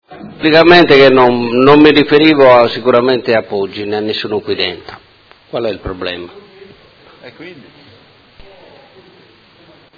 Dibattito su interrogazione, mozioni ed emendamenti riguardanti la situazione Società Italpizza S.p.A
Audio Consiglio Comunale